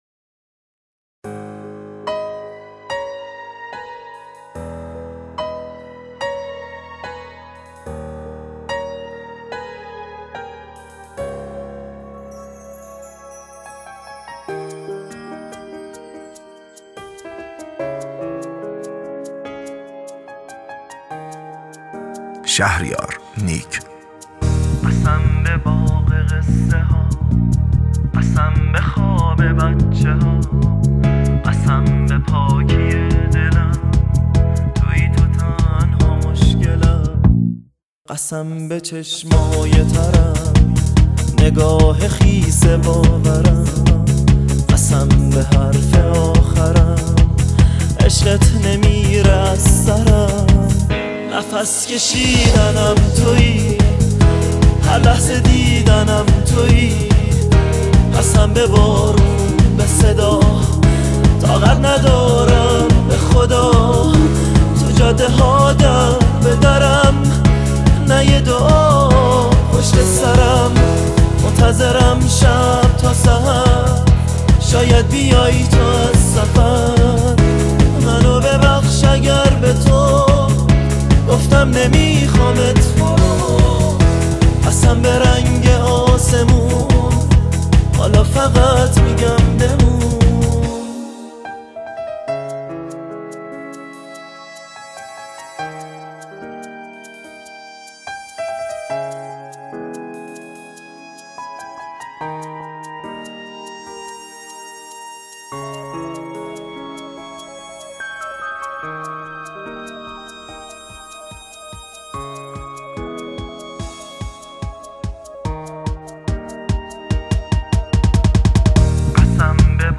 غمگین ، پاپ